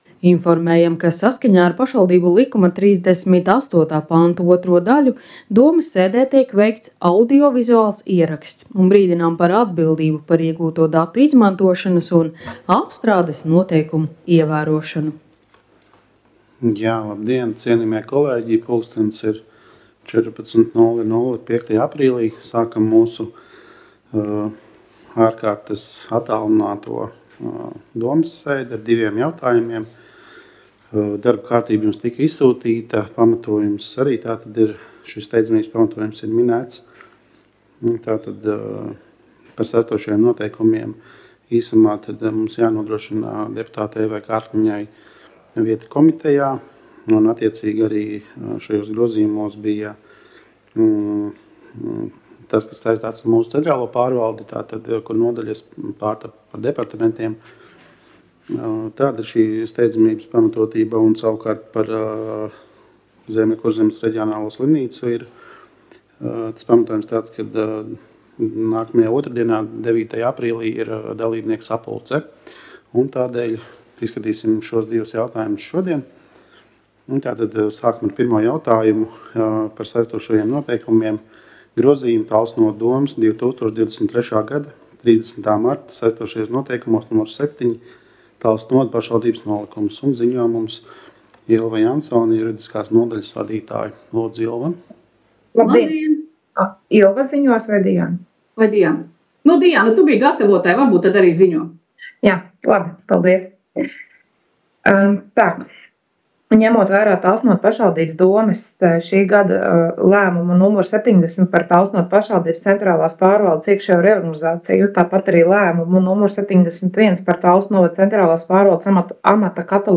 Domes sēdes audio